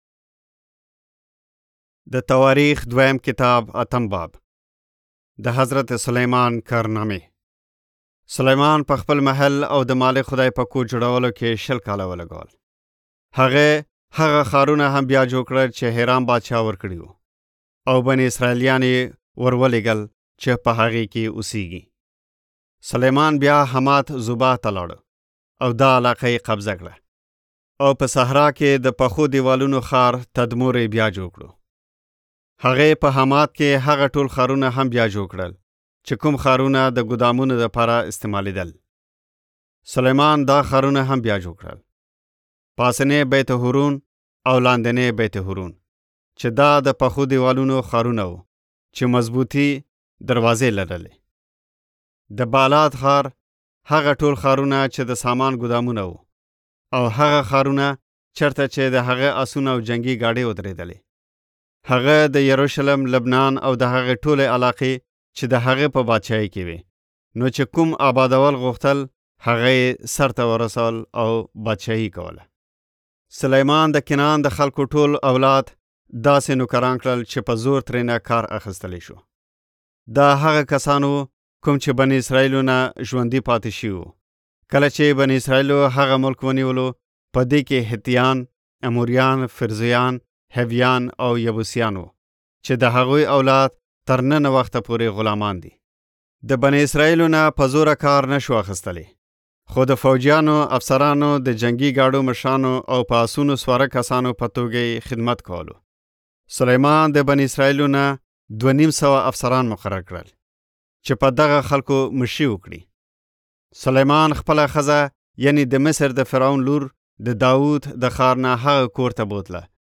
Yousafzai East Afghan East Afghan